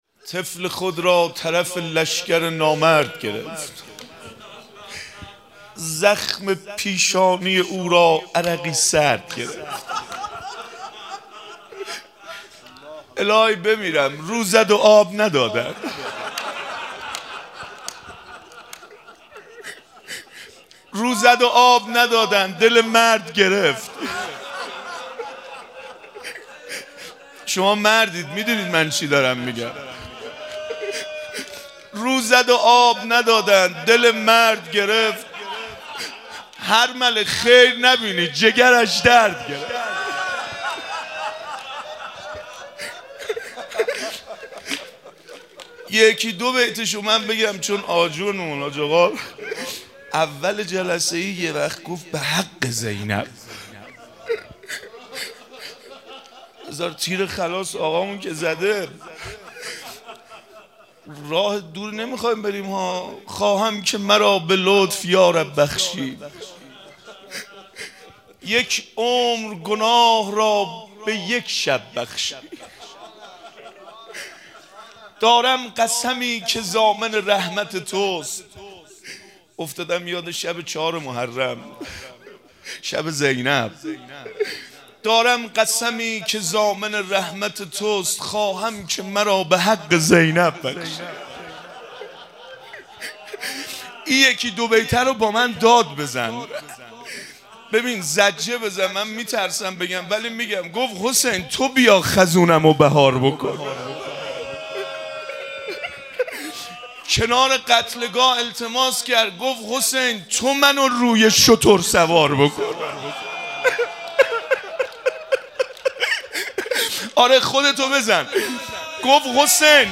روضه- طفل خود را طرف لشگر نامرد گرفت